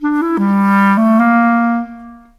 Rock-Pop 10 Clarinet 01.wav